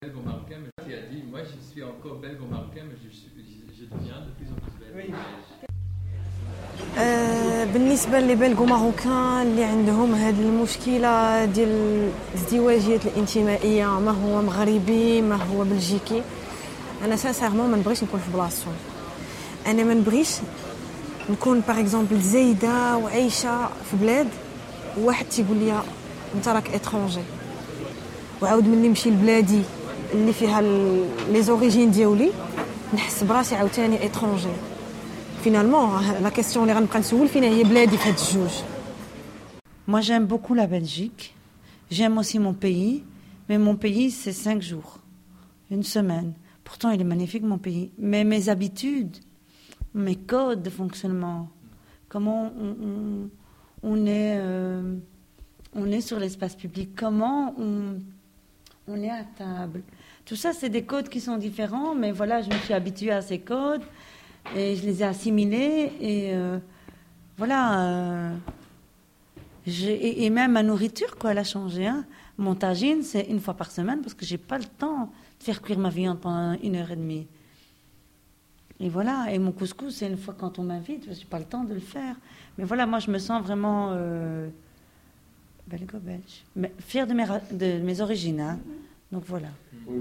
La trancription est la traduction française du Darija d’un extrait du film Place de Belgique, projeté au Magasin de mots le 7 avril 2011